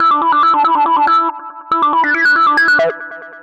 Index of /musicradar/future-rave-samples/140bpm
FR_Cheeka_140-E.wav